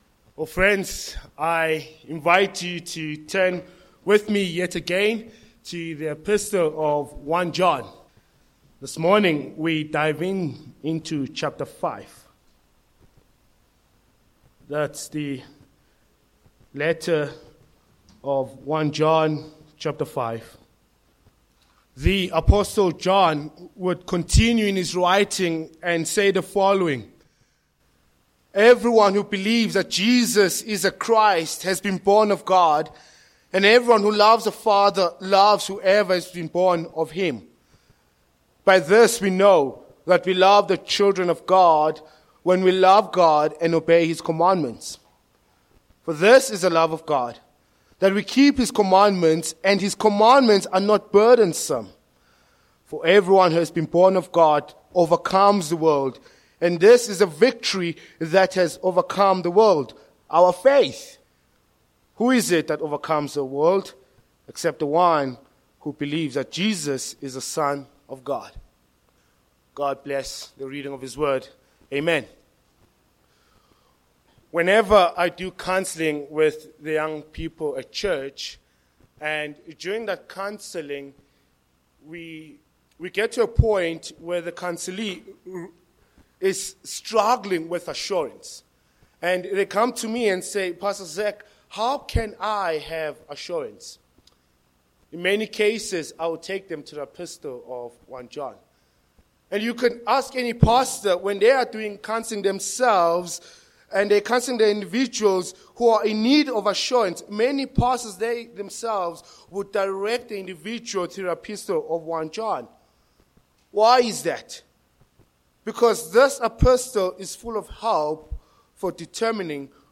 Sermon points: 1. Reborn by faith as a child of God v1a